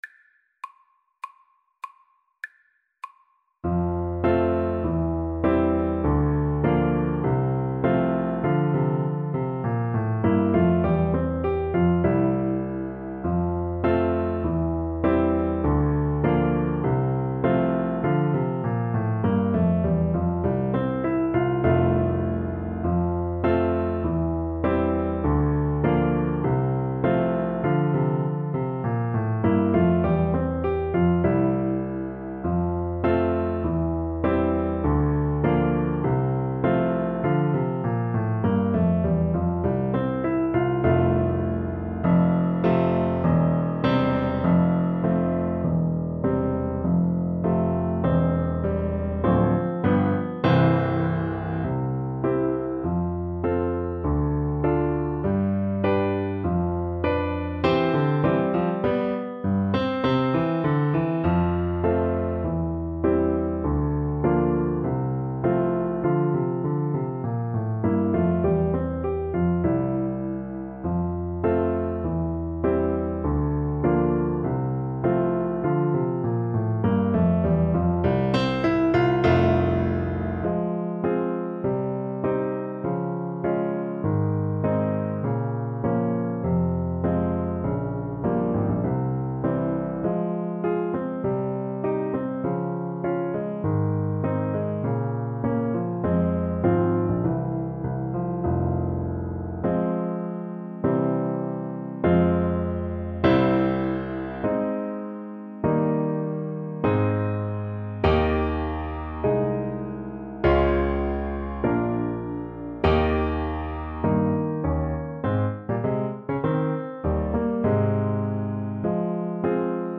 ~ = 100 Allegretto
Classical (View more Classical Trumpet Music)